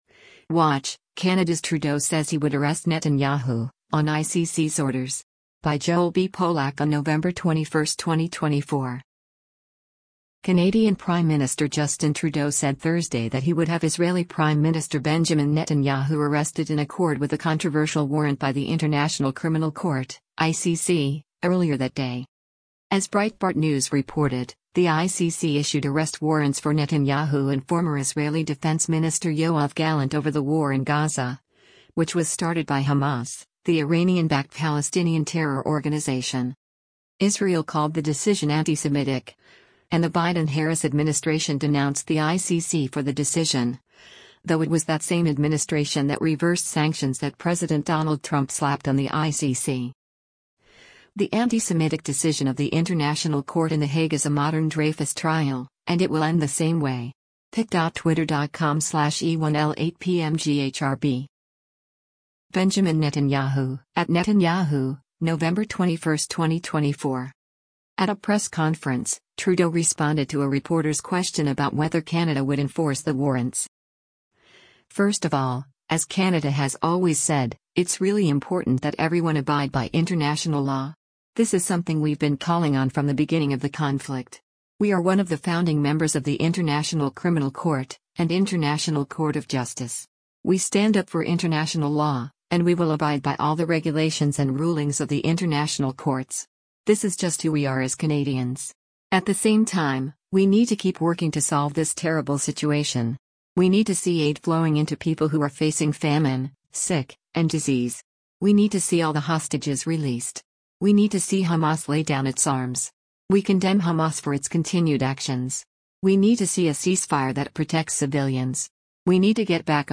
At a press conference, Trudeau responded to a reporter’s question about whether Canada would enforce the warrants:
Trudeau repeated his remarks in French, saying that everyone needed to respect the decisions of international institutions.